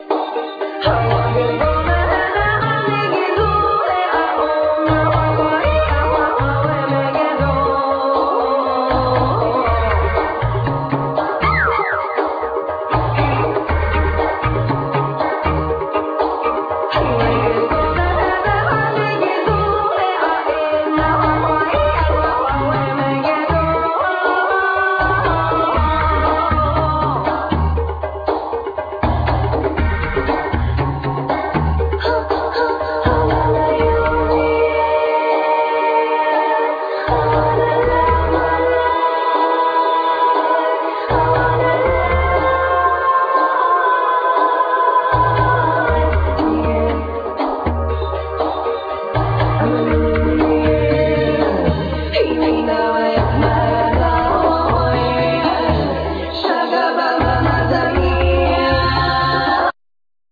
Vocal, Keyboards, Piano
Keyboards, Programming, Piano, TR-808
Bouzouki, Mandlin, Programming, Bass, Guitar, Vocal
Flugelhorn, Trumpet
Guitar, Drums
Violin, Viola, String arrangement
Cello